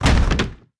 drop_big.ogg